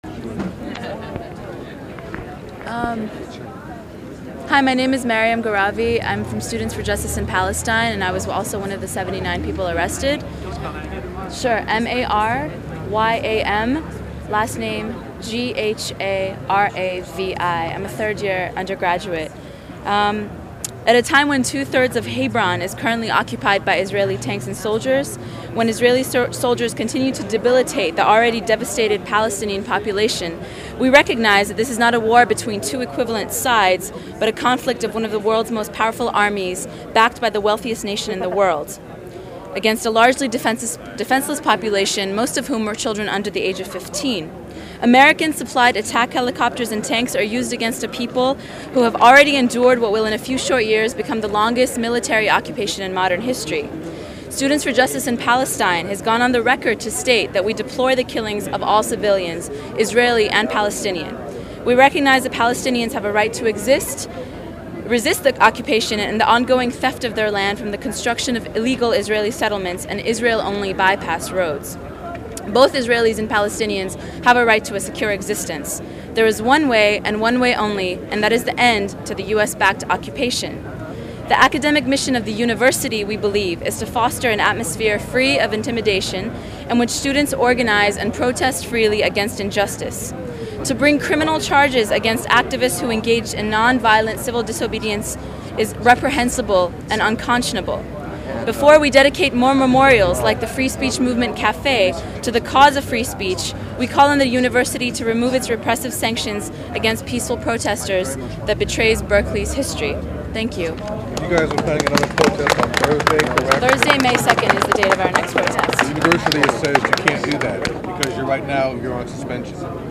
Statements from NLG and SJP from a 4/30 press conference held in front of the Berkeley courthouse